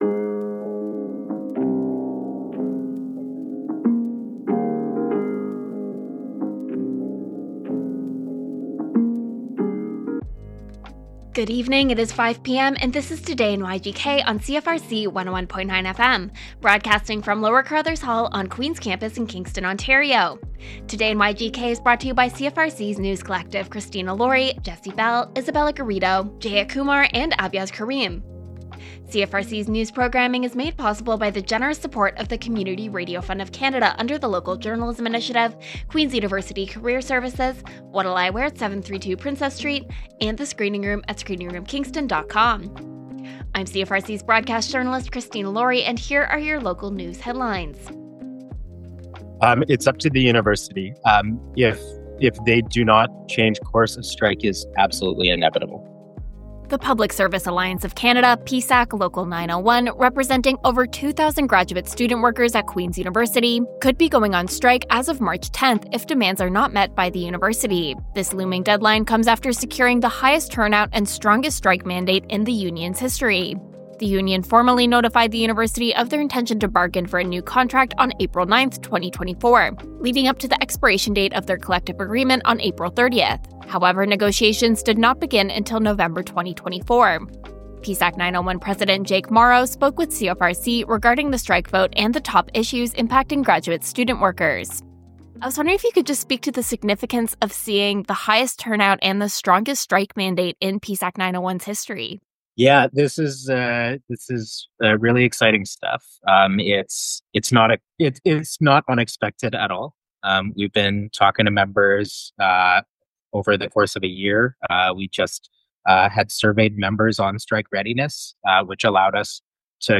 Local news with CFRC’s News Team.